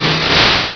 Cri de Blindalys dans Pokémon Rubis et Saphir.
Cri_0268_RS.ogg